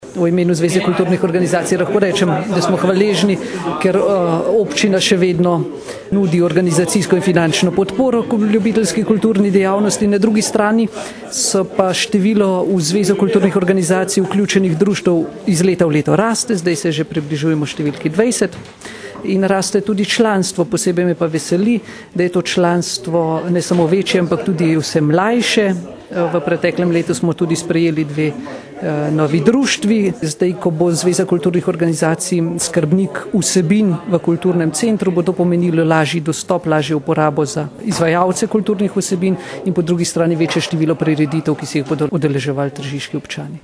izjava_zupanobcinetrzicmag.borutsajovicotrziskikulturi.mp3 (1,0MB)